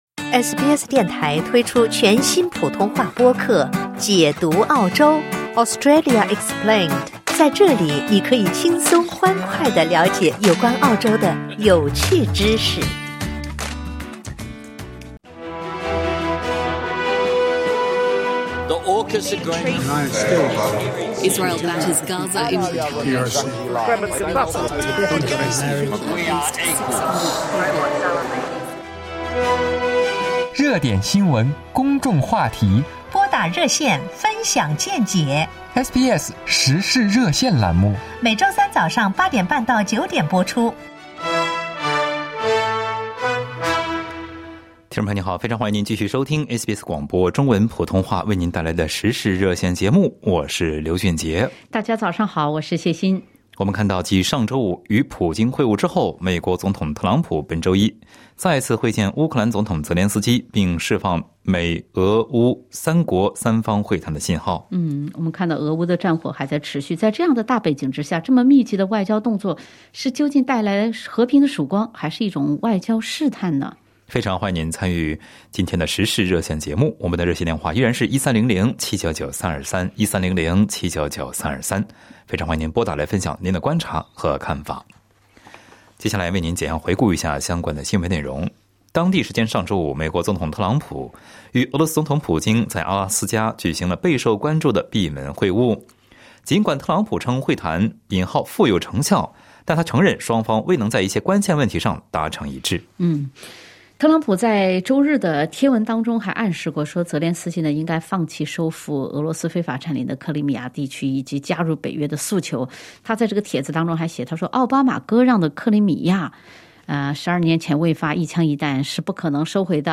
在本期《时事热线》节目中，听友们就此分享了看法。